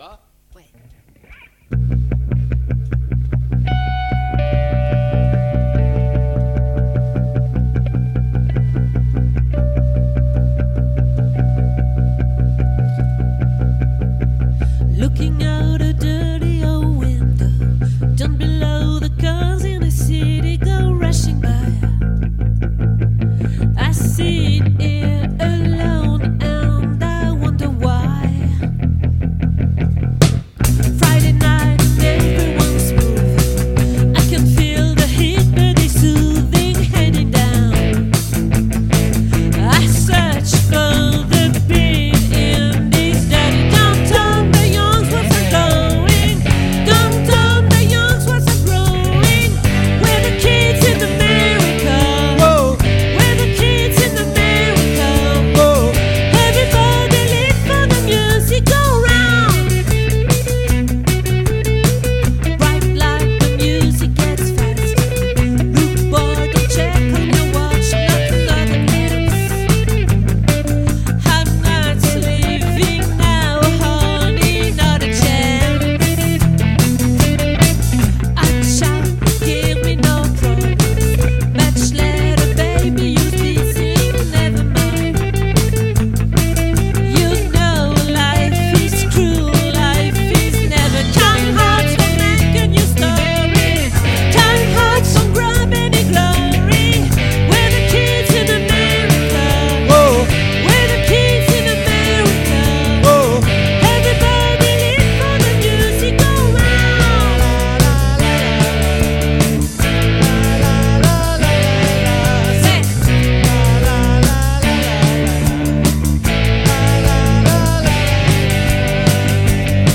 🏠 Accueil Repetitions Records_2024_02_20_OLVRE